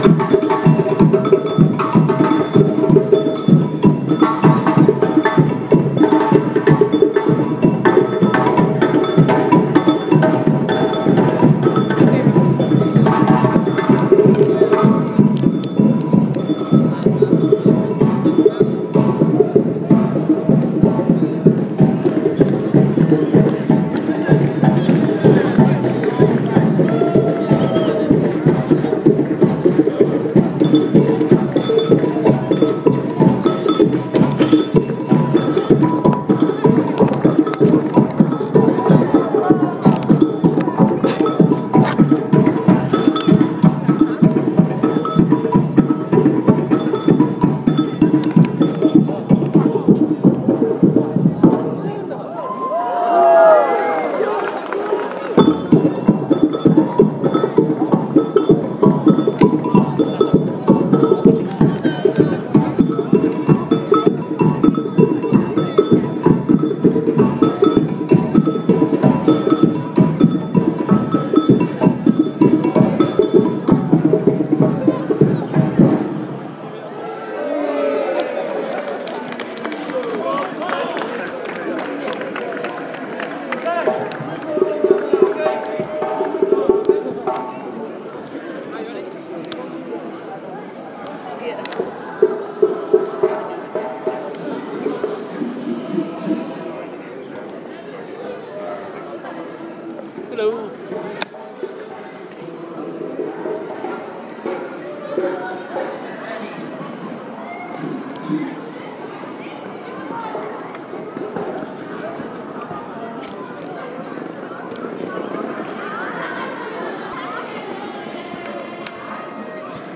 Briggate on Light Night.